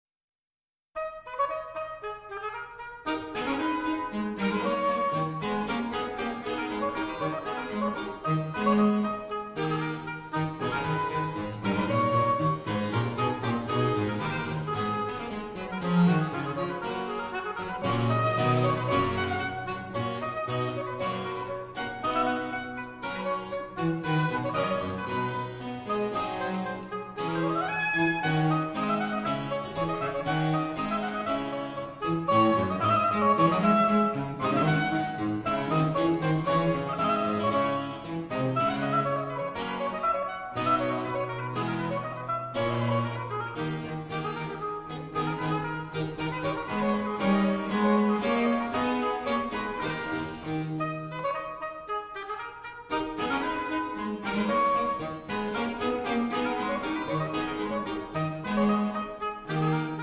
Oboe
Harpsichord
Cello